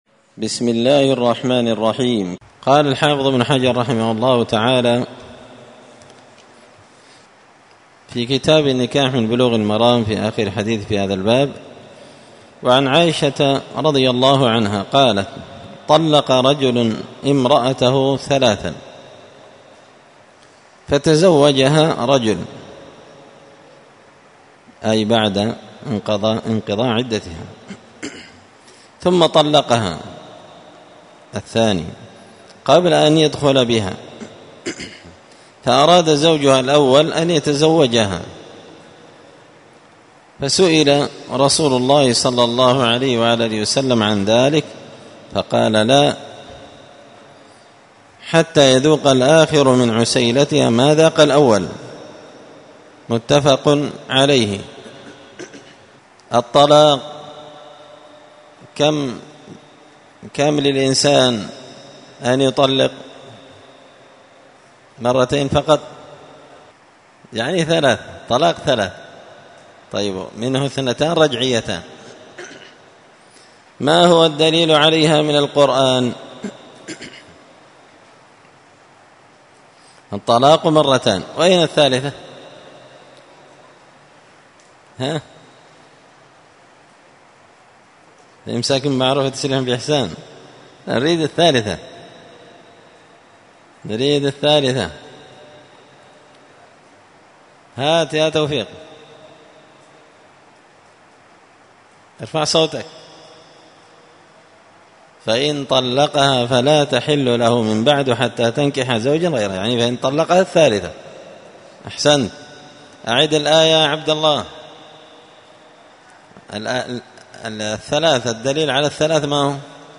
الدرس 15 تابع أحكام النكاح {تابع لنكاح التحليل}
مسجد الفرقان_قشن_المهرة_اليمن